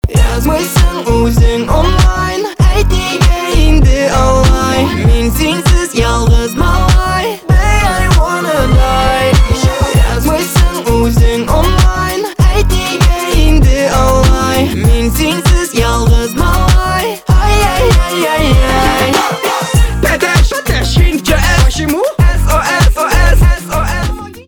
битовые , басы , гитара